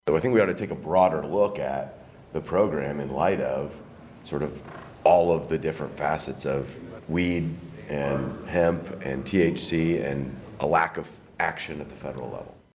SENATOR MIKE BOUSSELOT OF ANKENY SAYS A PROLIFERATION OF PRODUCTS THAT PRODUCE A HIGH ARE BEING SOLD ELSEWHERE.